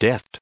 196_deft.ogg